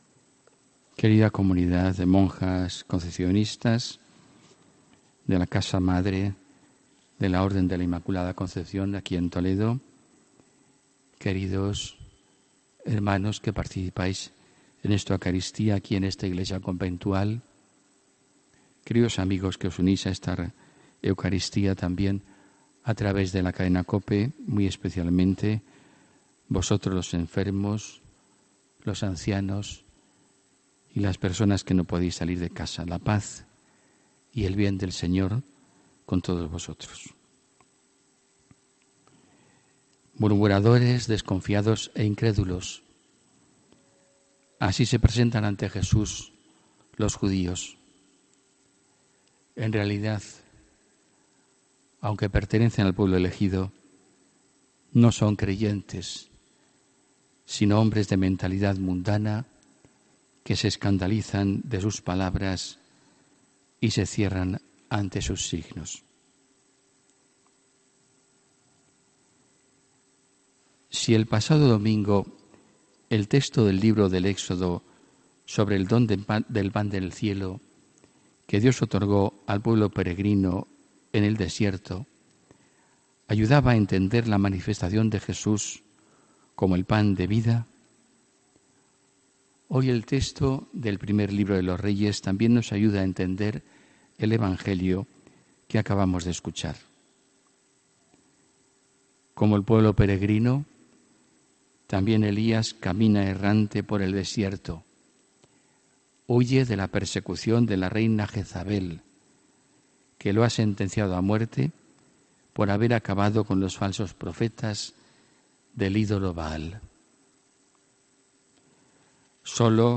HOMILÍA 12 AGOSTO 2018